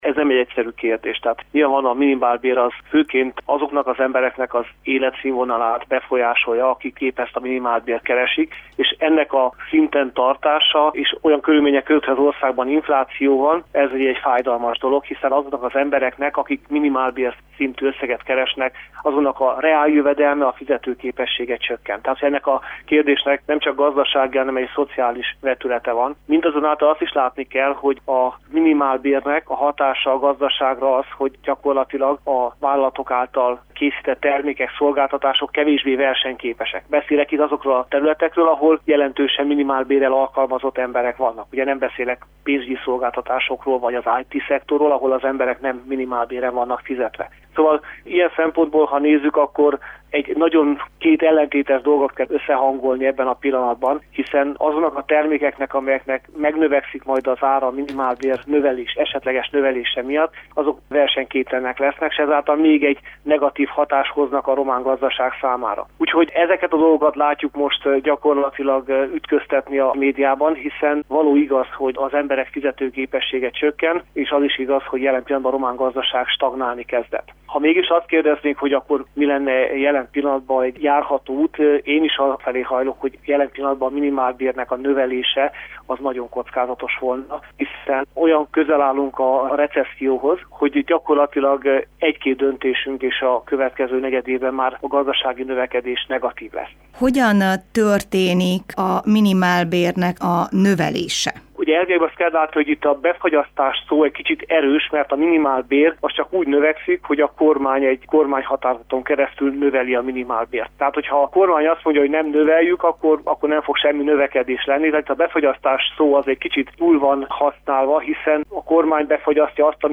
Vita van erről a koalícióban, mi közgazdászt kérdeztünk.